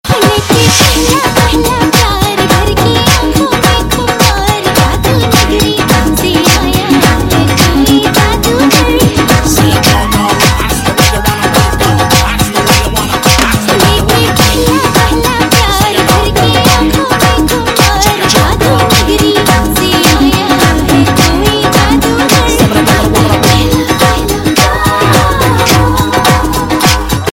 Navratri Ringtones